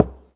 Knock12.wav